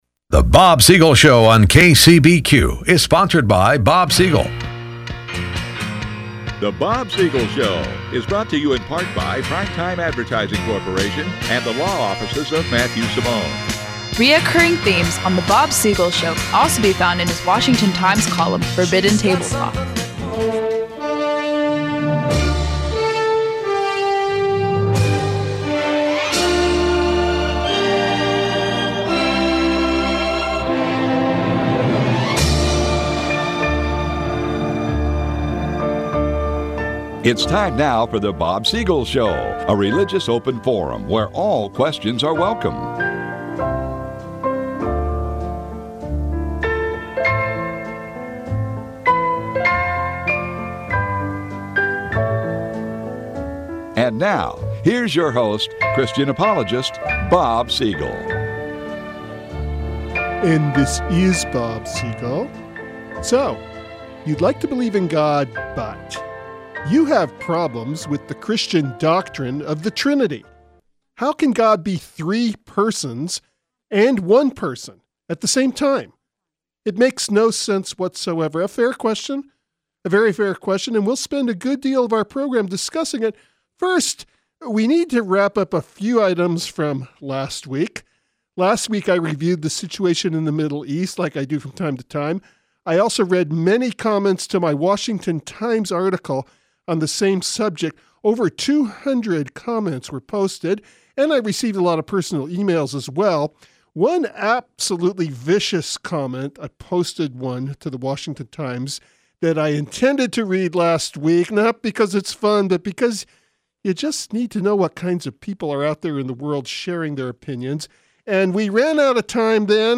SATIRICAL SKETCH: Tamar the Task Master Share this on Related Posts: When An Answer To A Bible Question Does Not Seem To Be Enough When People Stop Believing in God, They'll Start Believing in Anything!